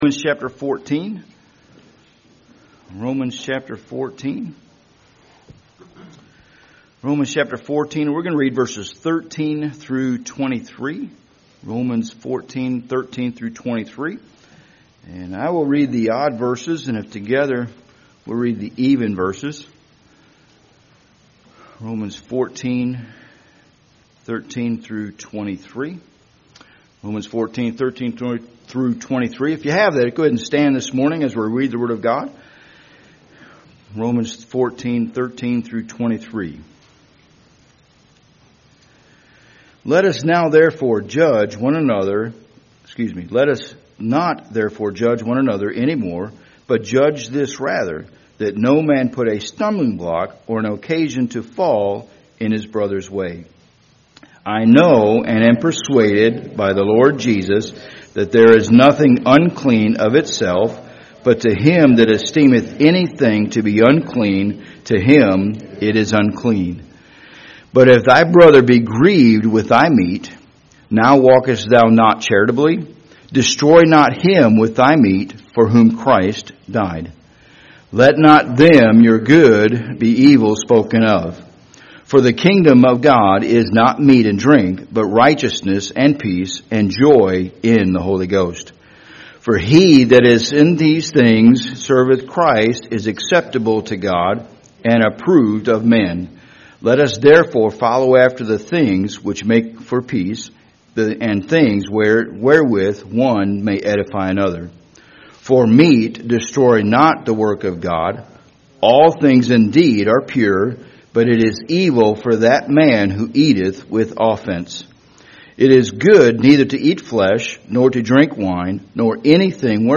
Passage: Romans 14:13-23 Service Type: Sunday Morning